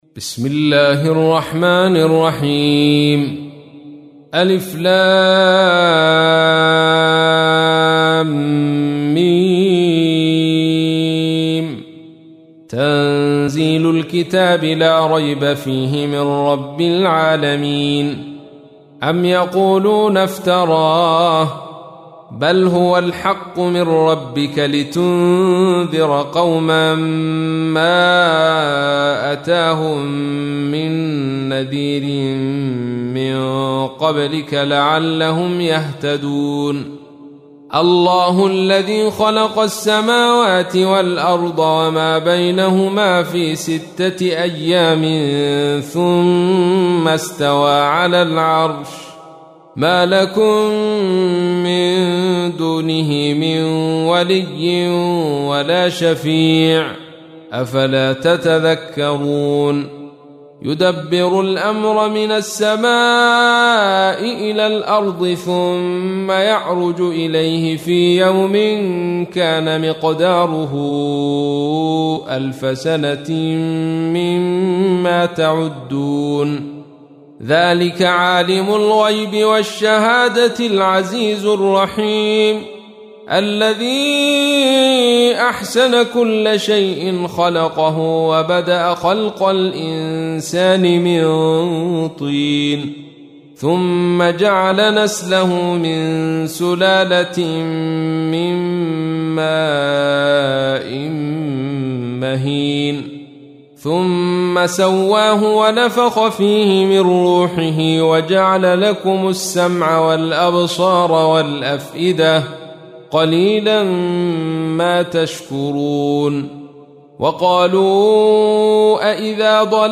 تحميل : 32. سورة السجدة / القارئ عبد الرشيد صوفي / القرآن الكريم / موقع يا حسين